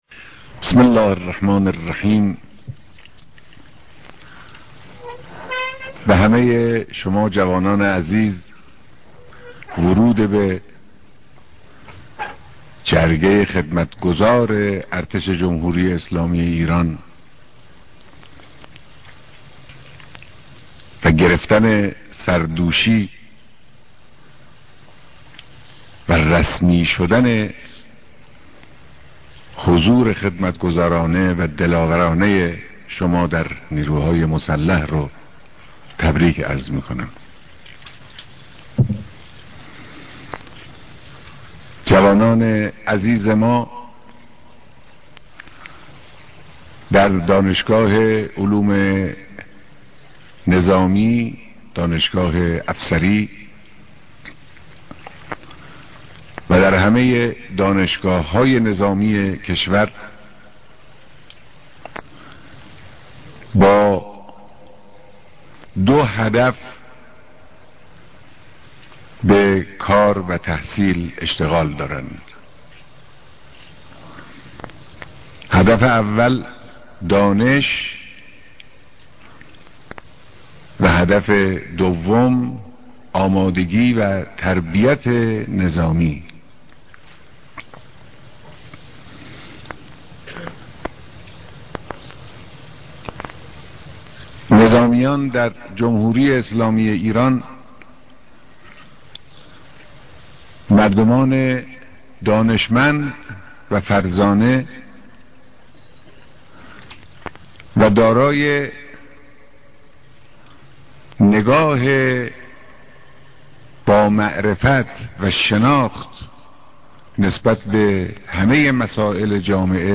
بيانات در مراسم تحليف و اعطاى سردوشى دانشگاه افسرى امام على (ع)